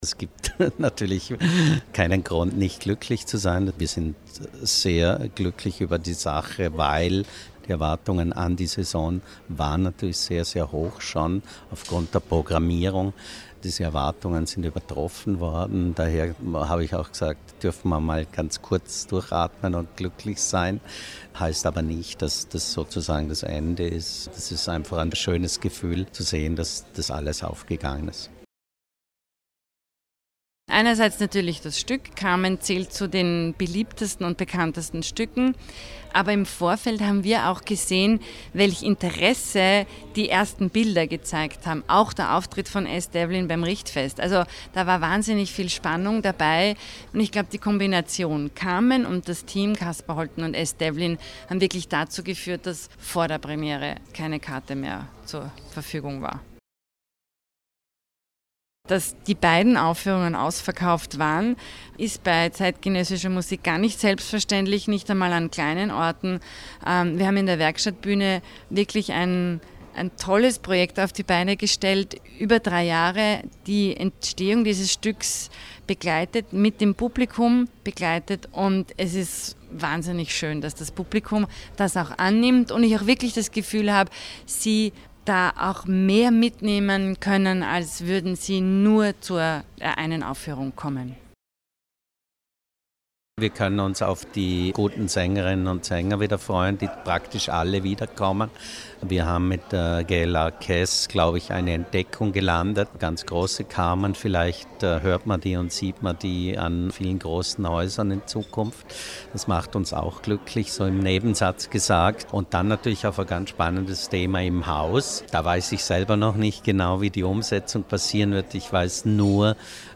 Pressekonferenz Vorläufige Bilanz - Feature